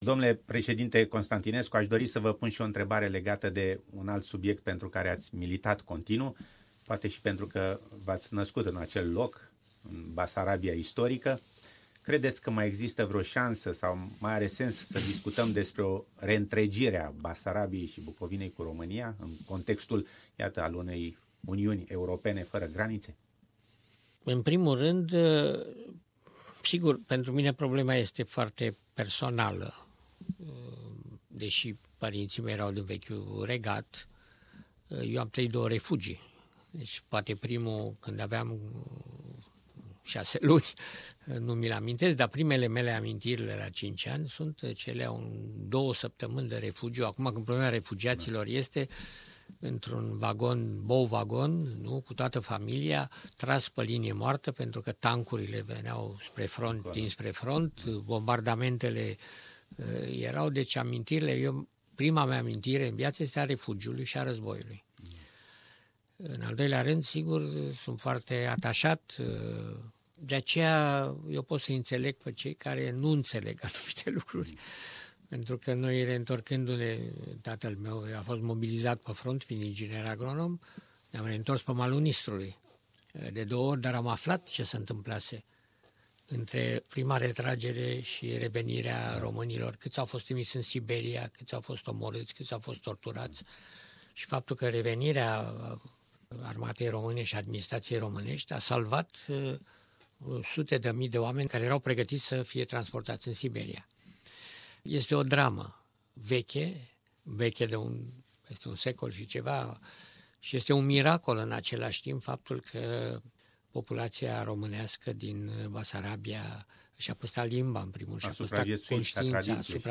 Interviu cu fostul Presedinte al Romaniei, Emil Constantinescu - pt.3